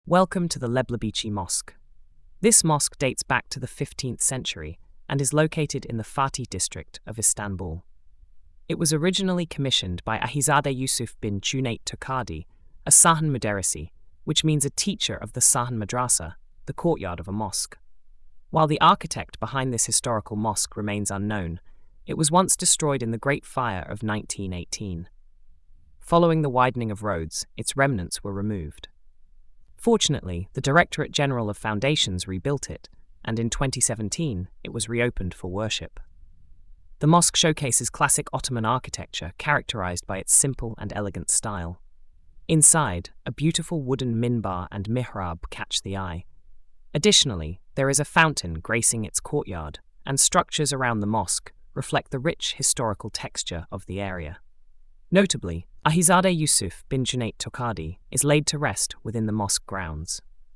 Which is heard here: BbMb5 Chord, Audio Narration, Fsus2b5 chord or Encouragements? Audio Narration